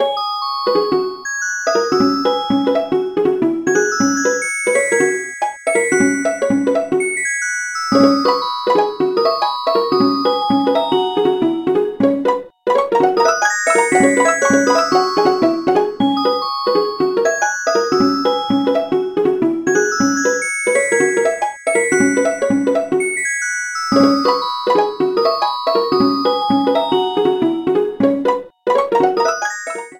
Music from the stage select screen